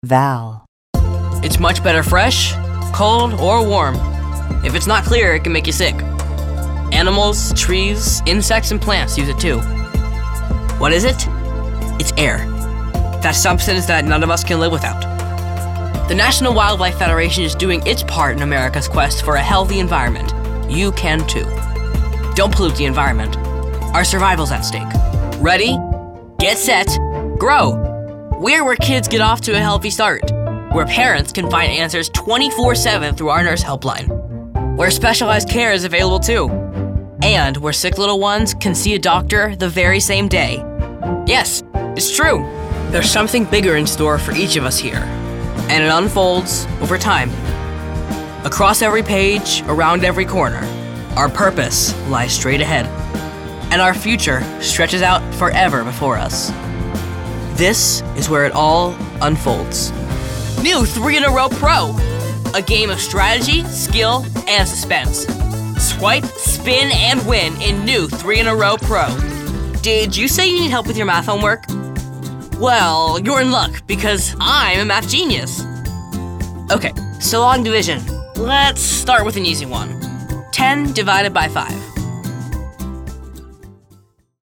A confident teenager that puts pep into your spots!
Showcase Demo
anti-announcer, confident, inspirational, mellow, real, teenager, thoughtful, young
announcer, high-energy, promo, teenager, upbeat